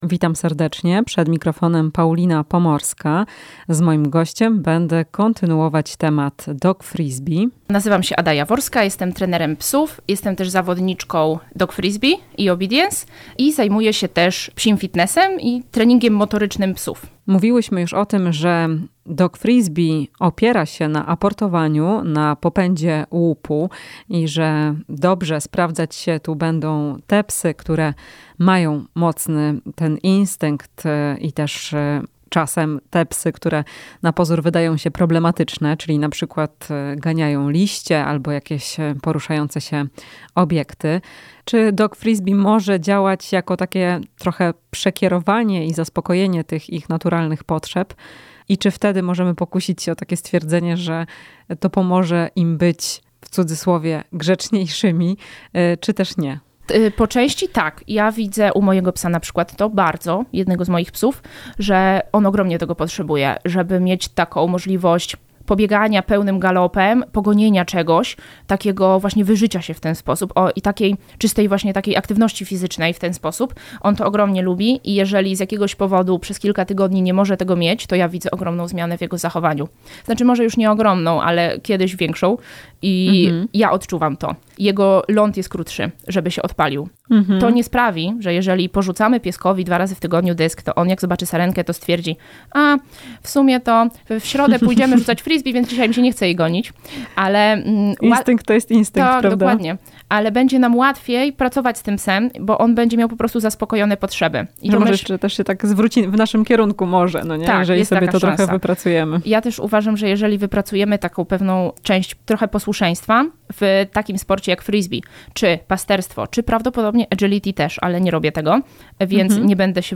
Rozmowa z trenerem psów